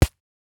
Body armor 1.wav